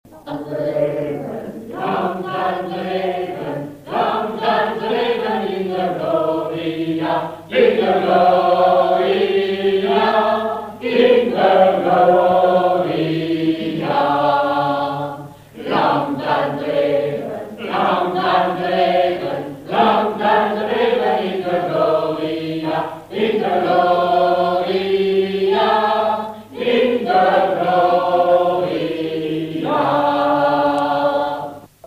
J’ai retrouvé une vieille bande magnétique sur laquelle je m’étais enregistré au début des années 2000 en train d’interpréter des chansons flamandes et néerlandaises au sein d’une chorale constituée dans le cadre du stage de langue néerlandaise que je suivais à l’époque.
Le mp3 que j’ai fait à partir de la bande magnétique :